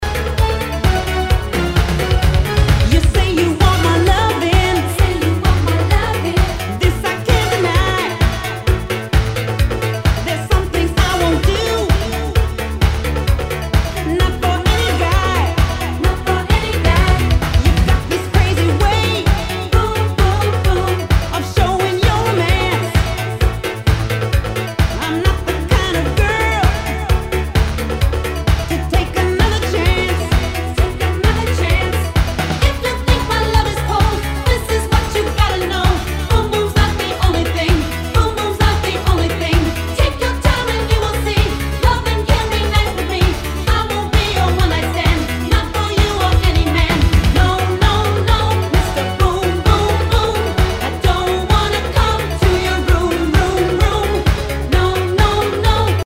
SOUL/FUNK/DISCO
ハイエナジー / シンセ・ポップ・ディスコ！